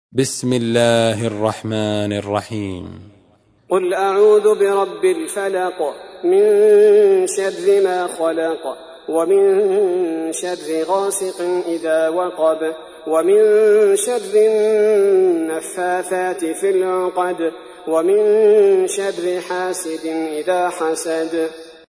تحميل : 113. سورة الفلق / القارئ عبد البارئ الثبيتي / القرآن الكريم / موقع يا حسين